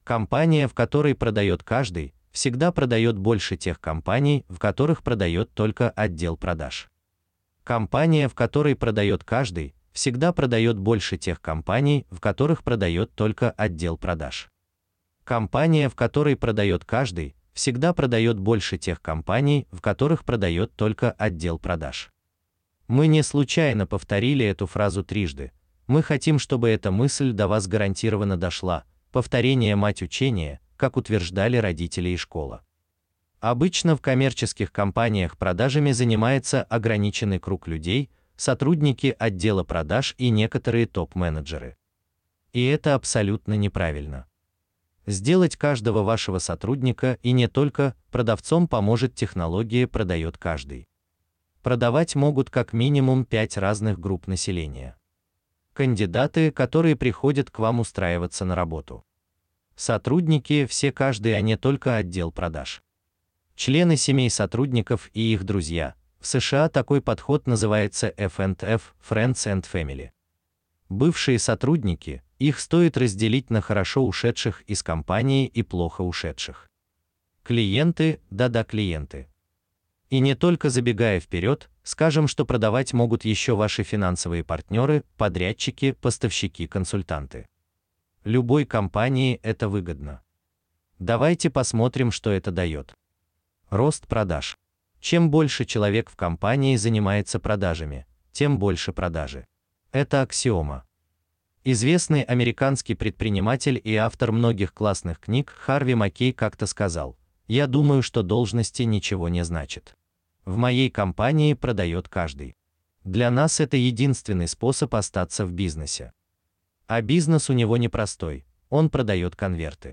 Аудиокнига Продает каждый!.. сотрудник и не только…